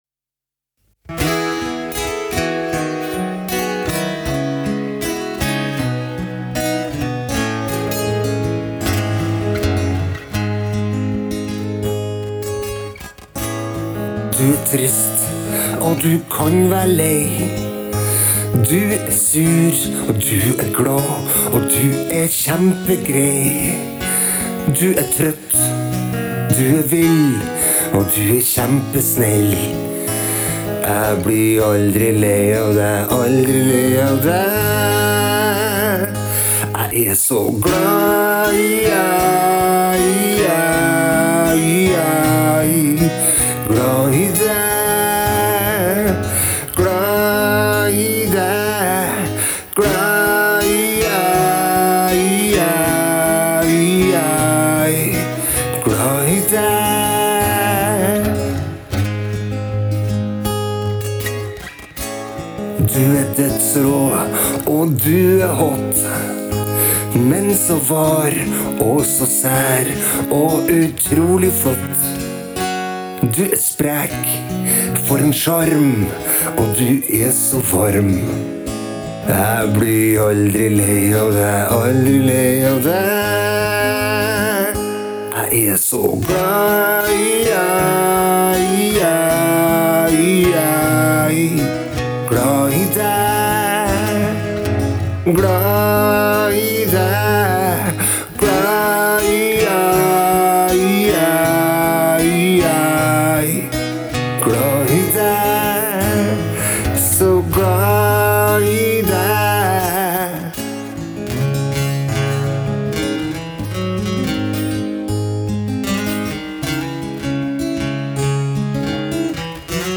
ei stor bøtte med Ovation gitar
Her er det 5 gitarer – og jeg er ikke så god, derfor tok det laaang tid.
Burde selvfølgelig vært noen korstemmer også, men én sang per dag?